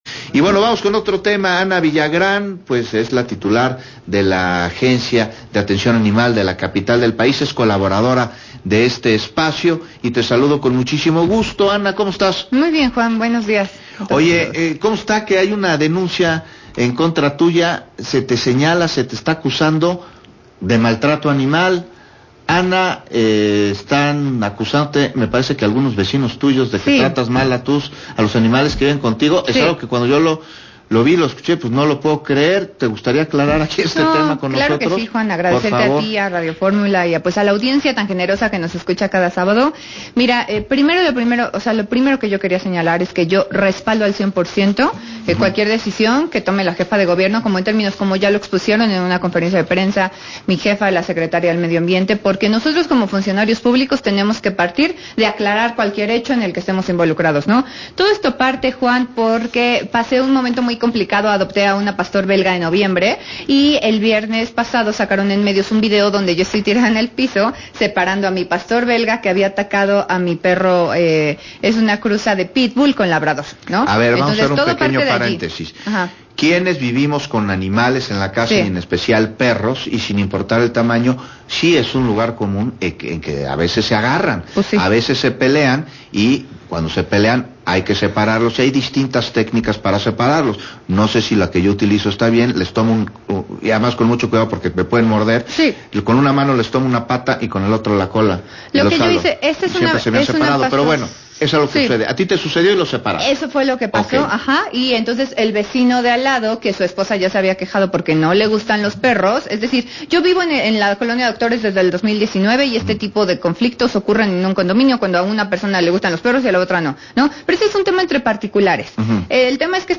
Entrevista a Ana Villagrán, titular de la Agencia de Atención Animal de la CDMX, sobre su acusación de maltrato animal.